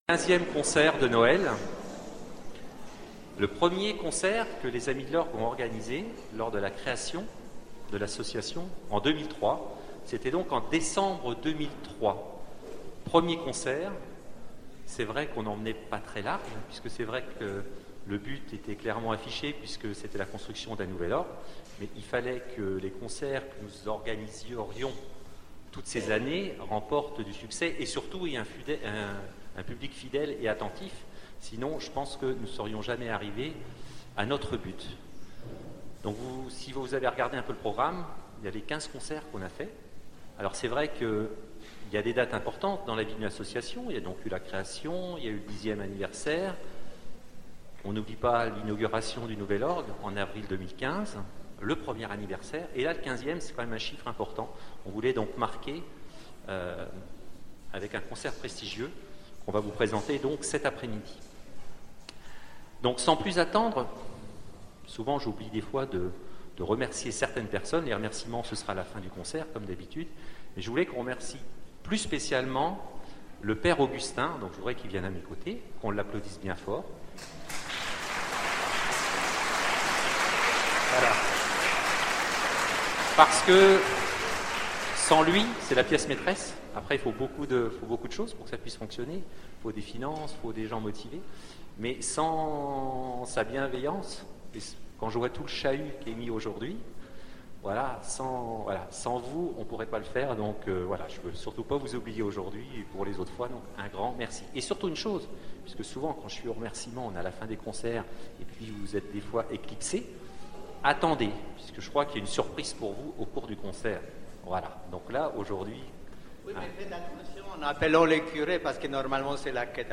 Concert de Noël Artenay
Concert de Noël des amis de l'Orgue d'Artenay